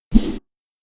Melee Weapon Air Swing 4.wav